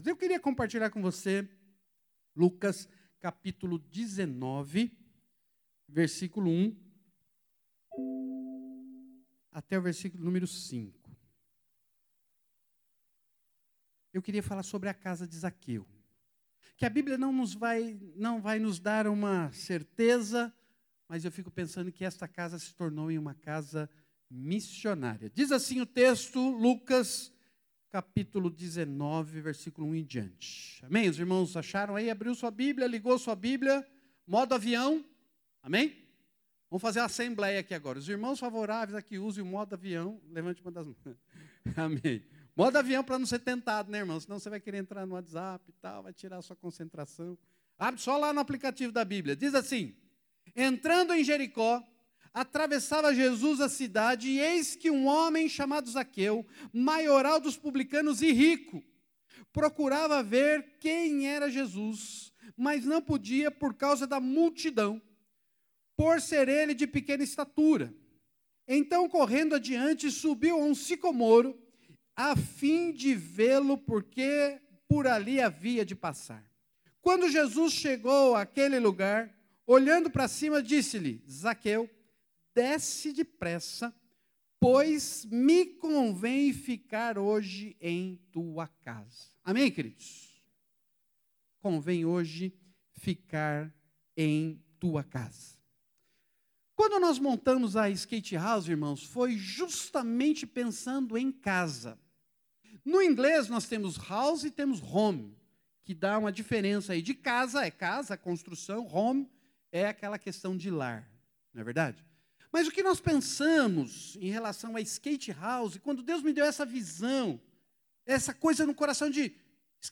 Culto da Conferência Missionária 2019